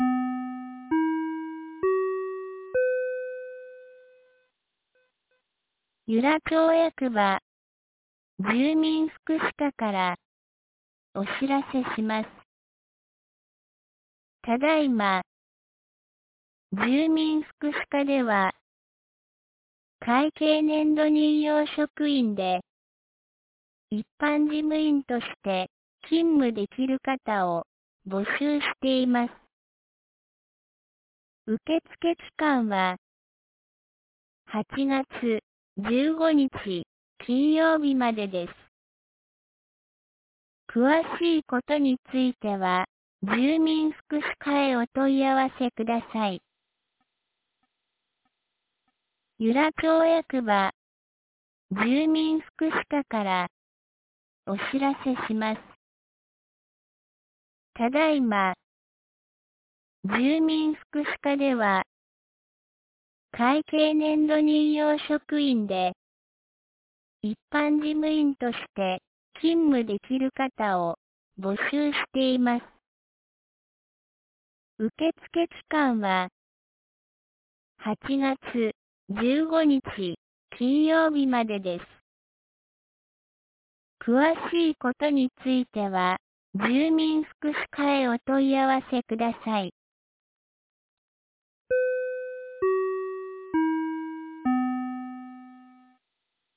2025年08月02日 07時52分に、由良町から全地区へ放送がありました。